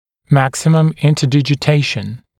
[‘mæksɪməm ˌɪntədɪʤɪ’teɪʃn][‘мэксимэм ˌинтэдиджи’тэйшн]максимальное правильное смыкание, положение максимальных контактов между зубными рядами